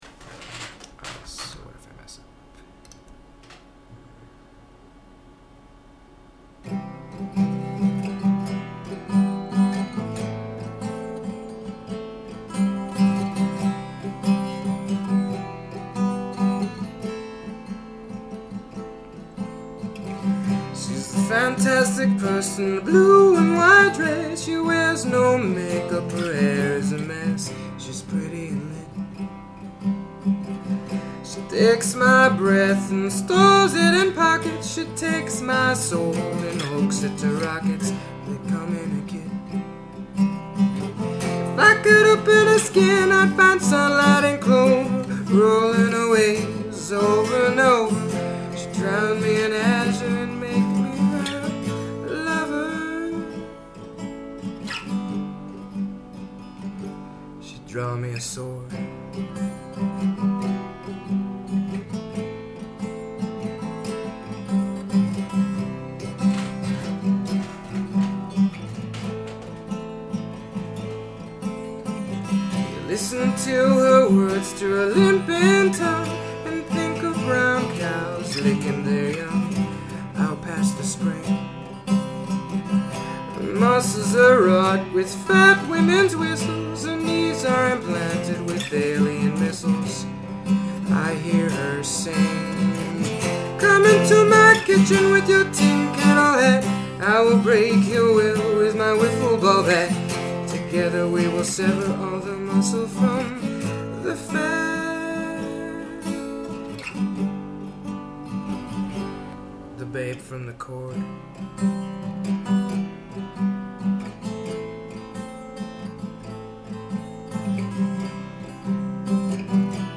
Please excuse the bad recording, I just used the built in computer microphone and the high notes get a little loud sometimes.